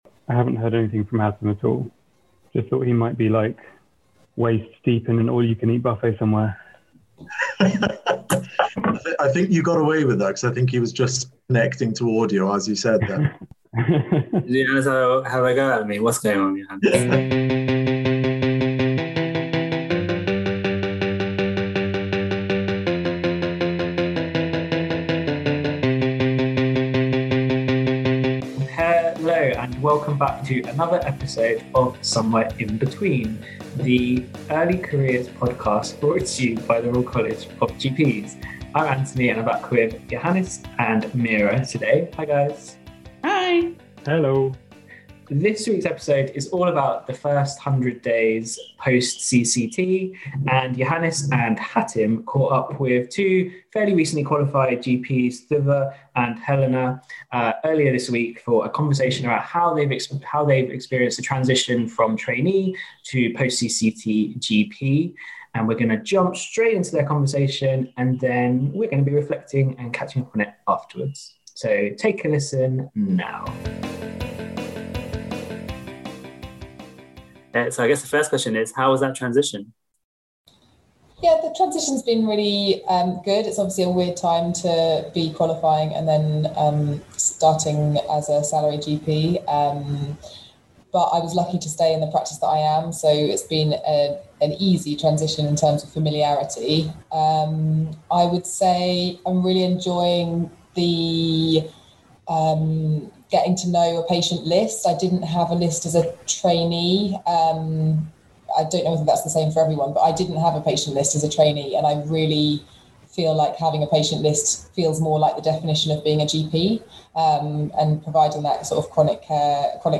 chat to two newly-qualified GPs